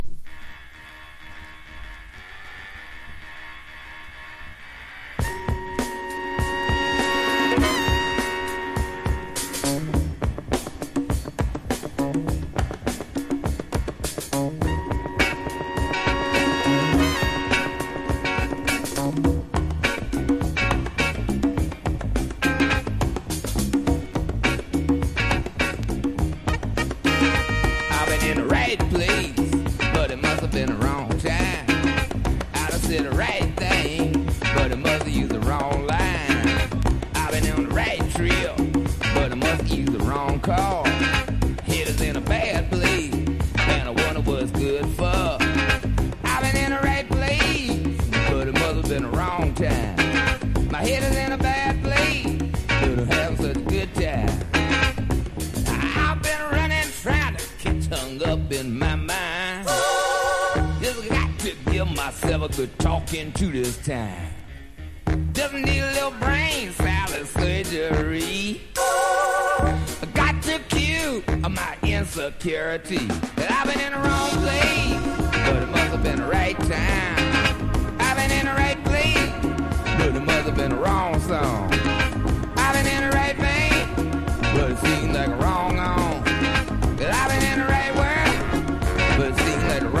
RARE GROOVE# FUNK / DEEP FUNK